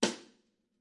VSCO 1打击乐资料库 鼓 " 小鼓（小鼓2 rimshot f 2
Tag: 边敲击 撞击声 小鼓 VSCO-2 单注 多重采样